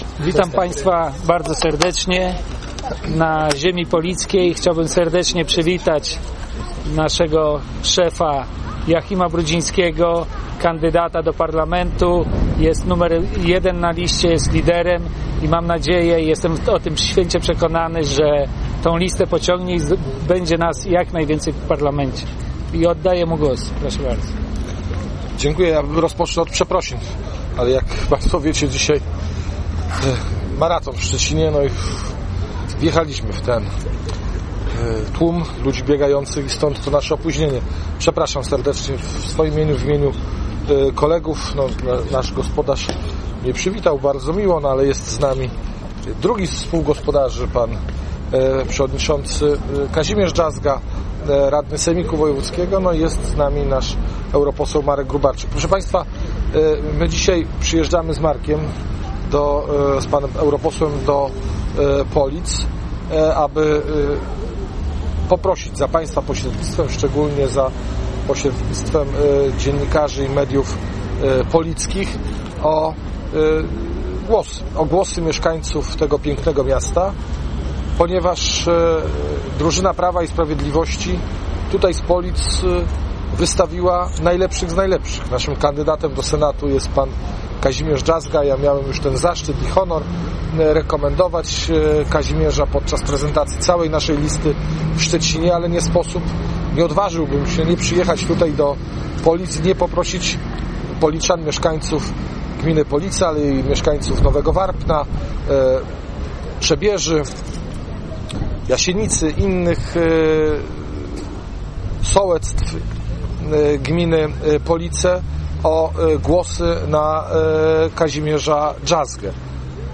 konferencja_pis.mp3